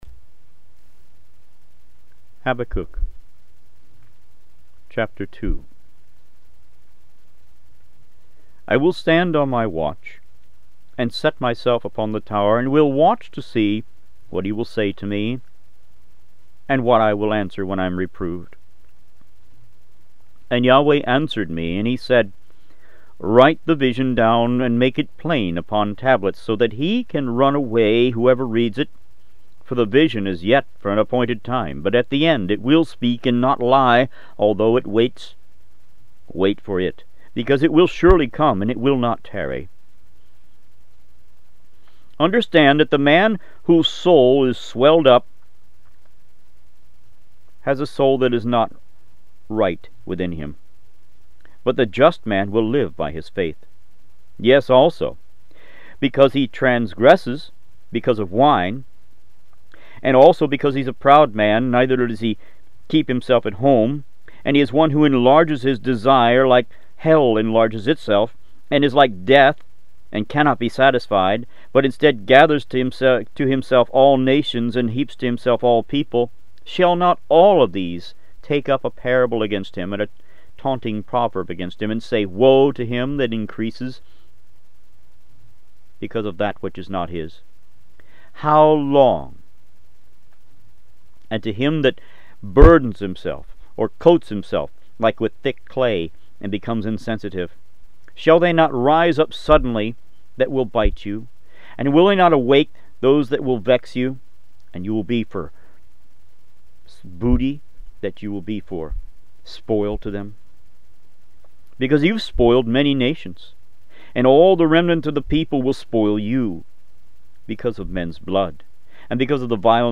Audio Bibles > Tanakh - Jewish Bible - Audiobook > 35 Habakkuk